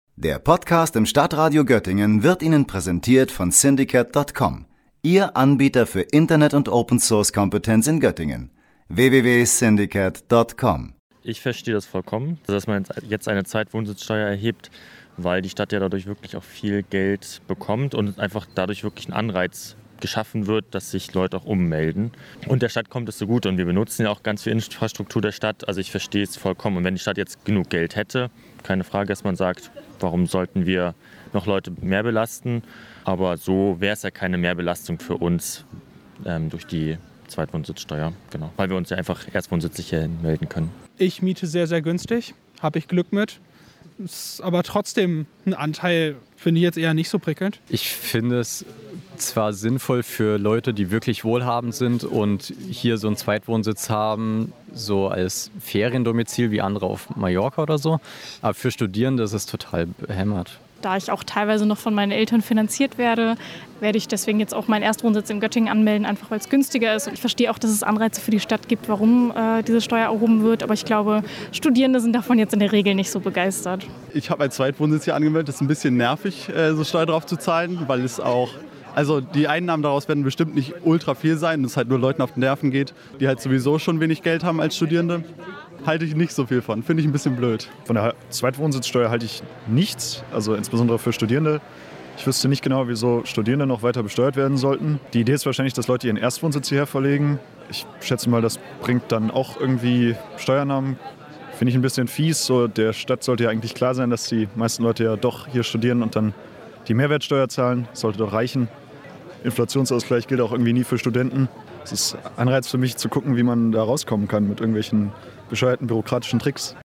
Wir haben betroffene Studierende nach ihrer Meinung zu der Zweitwohnsitzsteuer gefragt.